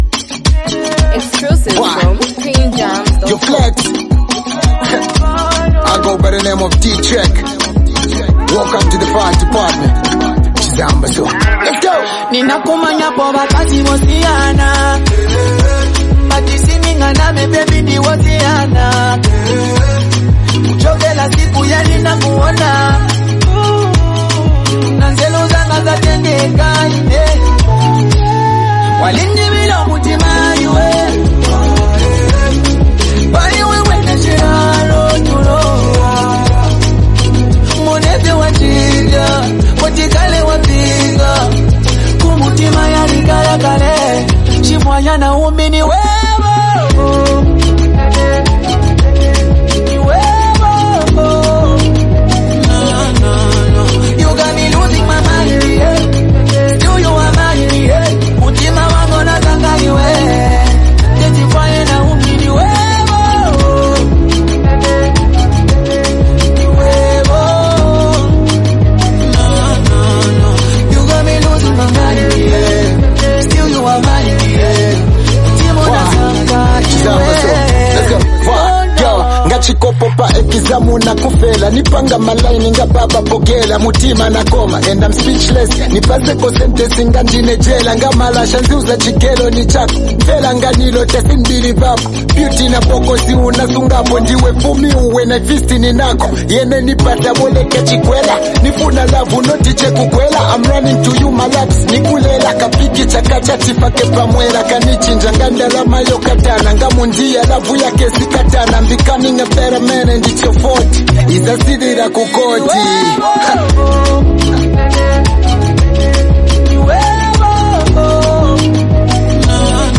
heartfelt love anthem that blends rap and melodic vocals